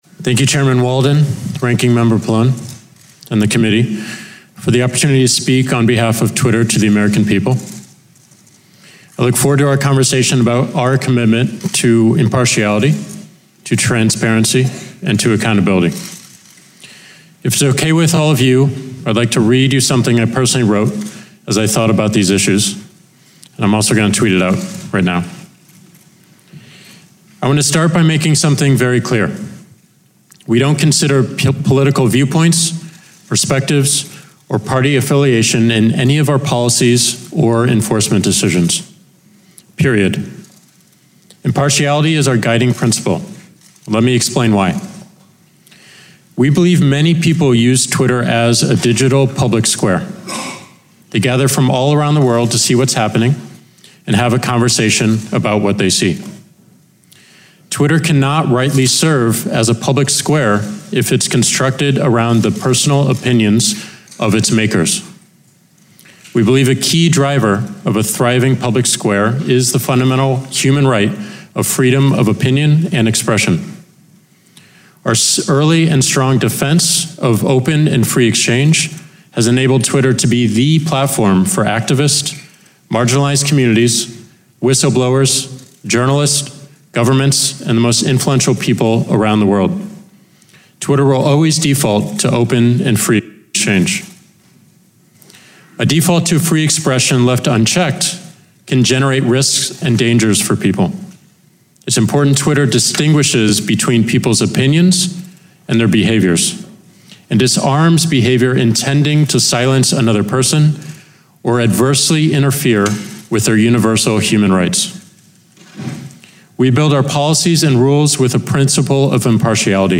delivered 5 September 2018, Washington, D.C.
Audio Note: AR-XE = American Rhetoric Extreme Enhancement
Original audio feed had a discernible hum in one channel. That channel was removed and the clean channel duplicated.